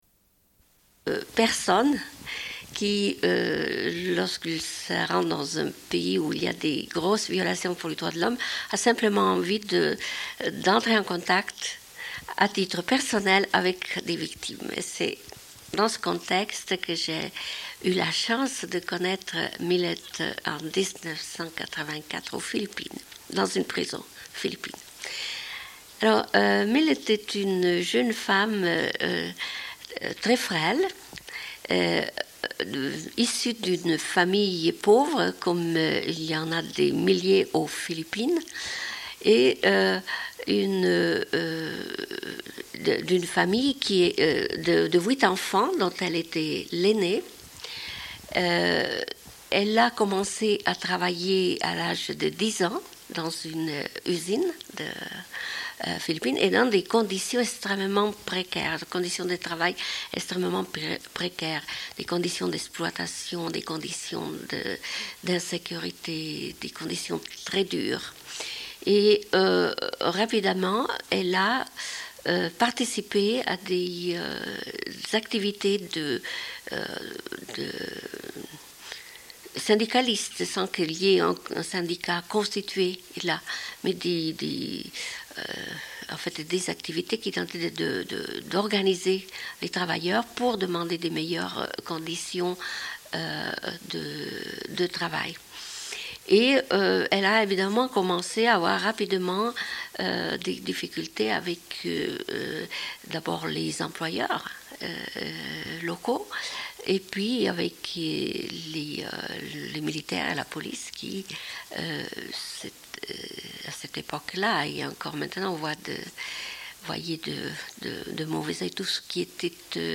Une cassette audio, face A30:54
Début de l'émission manquant.